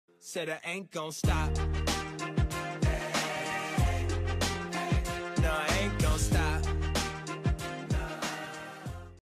sol-aint-gon-stop-notification-alert-sound.mp3